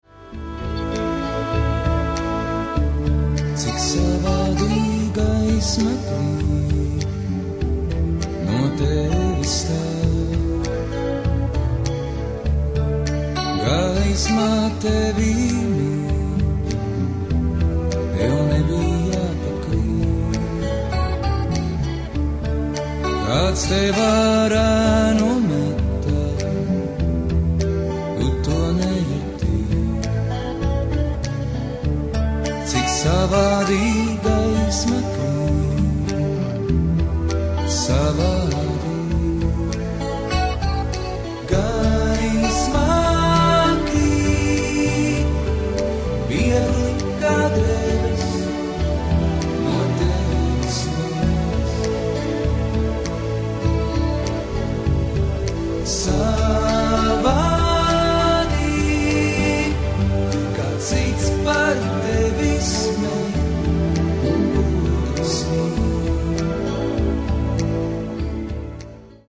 Here’s a recording of part of a song in a mystery language.
Especially when the music is masking the singer’s voice.